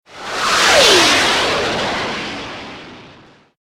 Missile Pass By 2